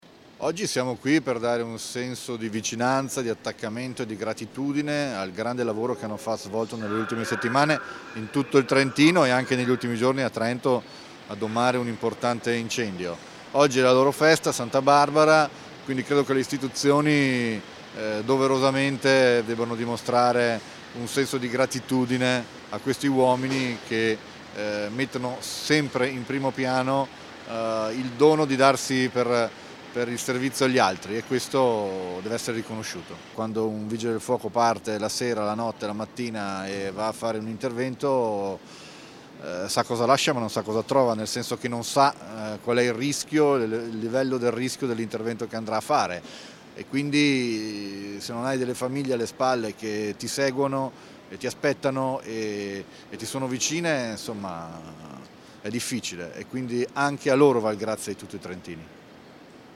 Presso la caserma del Corpo Permanente di Trento